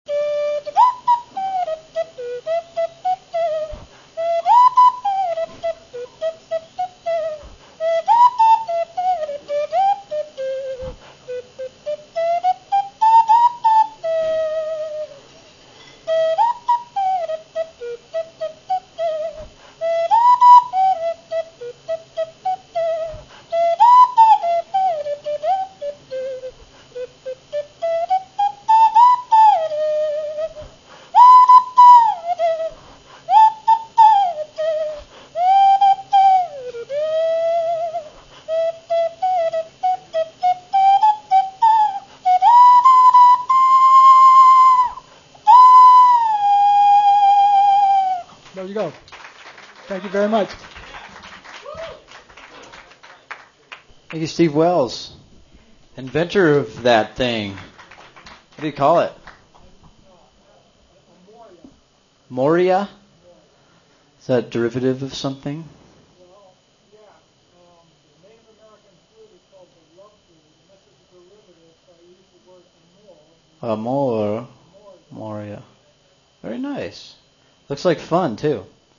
Mercury Café Open Mic Nite